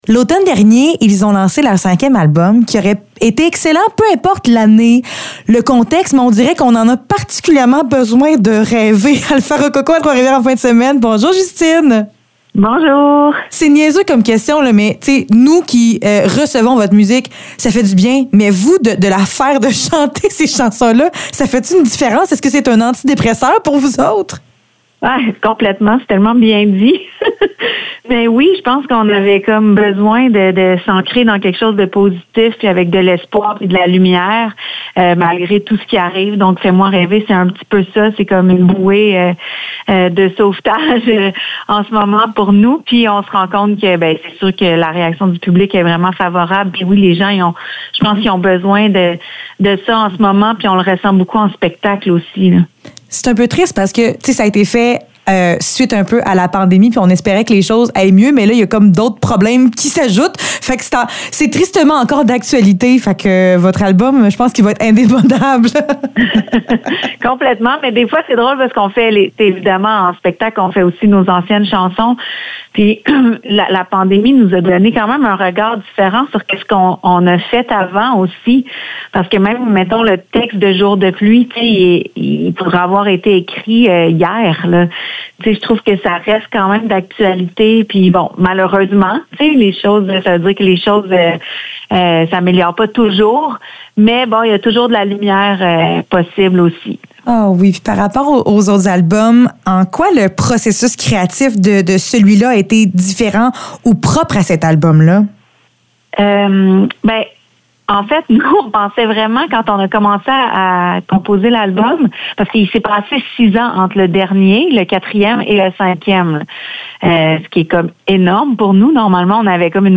Entrevue avec Alfa Rococo